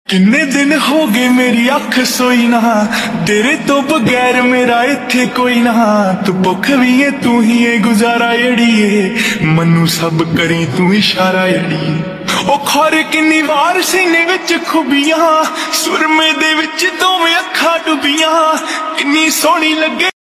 Only vocals No Music